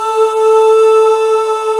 Index of /90_sSampleCDs/Club-50 - Foundations Roland/VOX_xMaleOoz&Ahz/VOX_xMale Ahz 1M
VOX XBH AH0B.wav